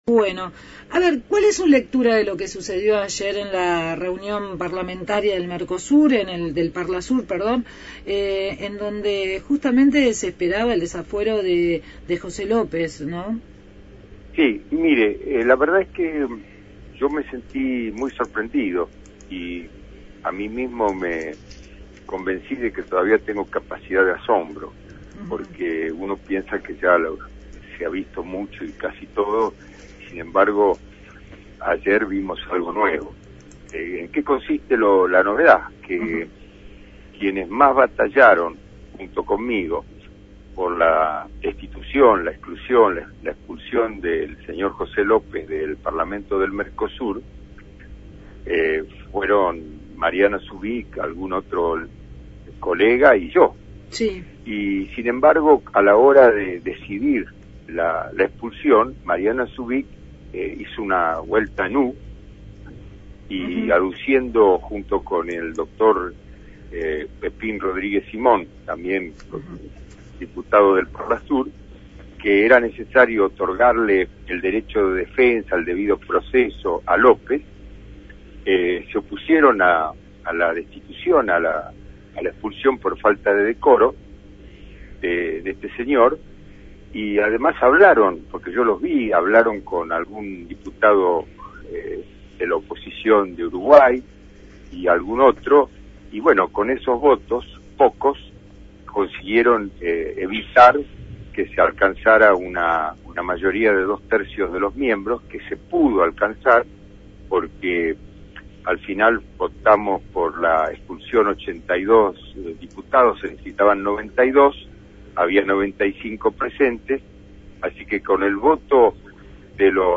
Entrevista a Alberto Asseff, diputado del Mercosur por el Frente Renovador se refirió al escándalo del monasterio de General Rodríguez en el que el ex secretario de Obras Públicas, José López, fue detenido con más de u$s 8,5 millones en fajos termosellados.